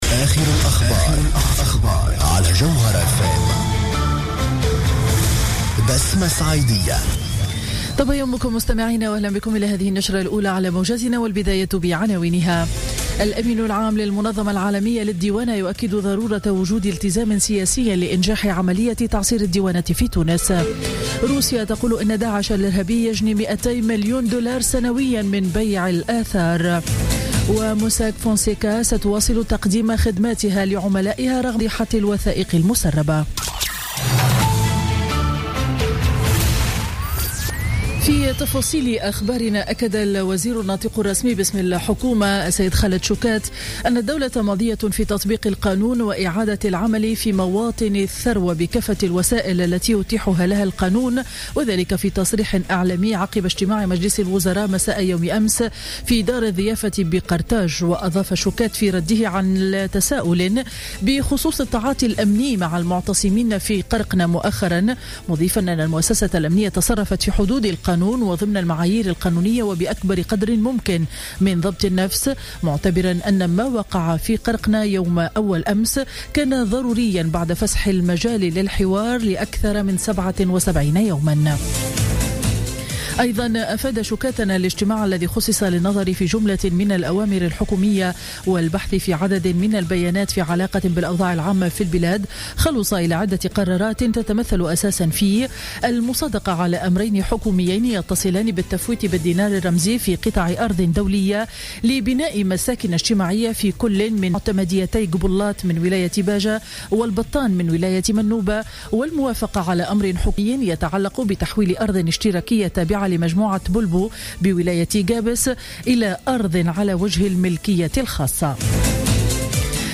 نشرة أخبار السابعة صباحا ليوم الخميس 7 أفريل 2016